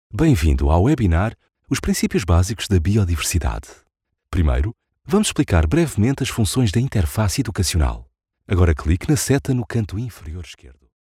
portuguese voice over
locutor portugués